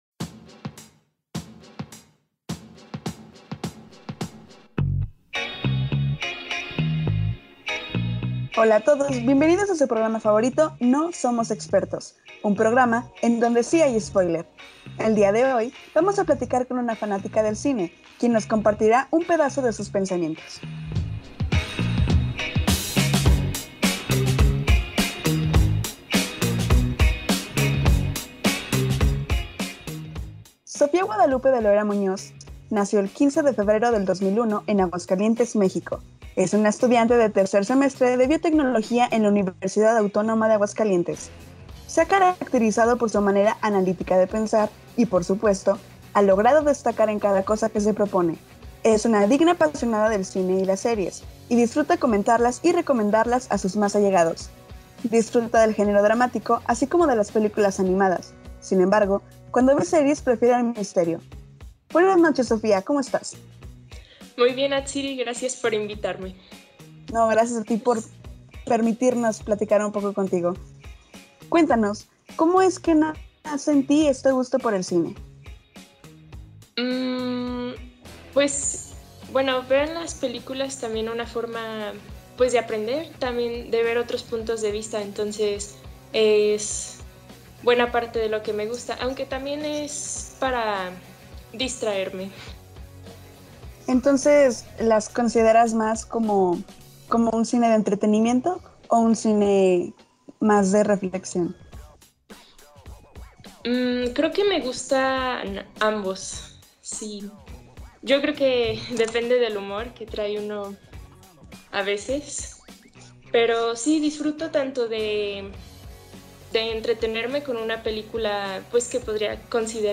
Entrevista sobre el Cine y la Sociedad.